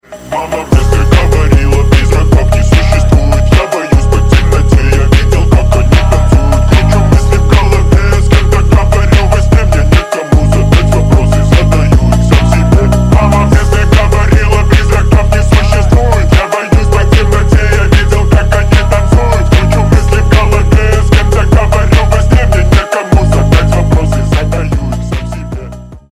Громкие Рингтоны С Басами
Рэп Хип-Хоп